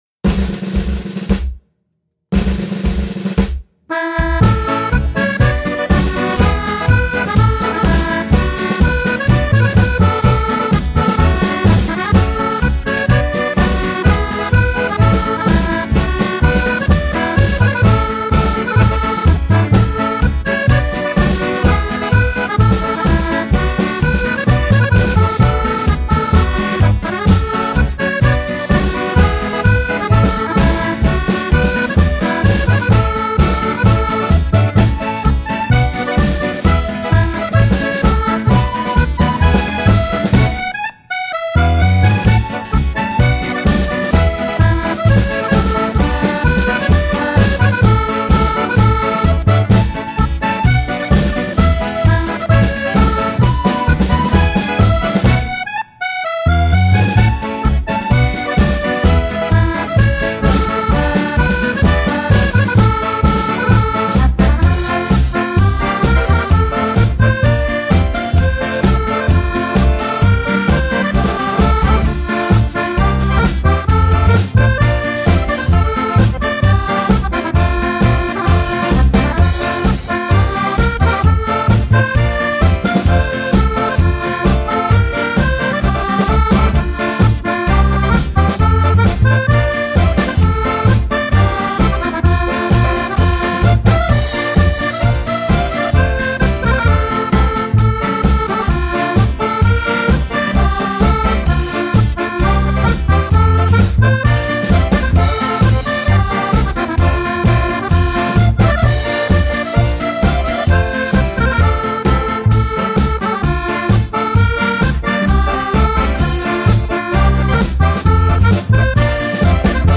Accordion
Drums and Guitar